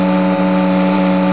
speederbike_engine.wav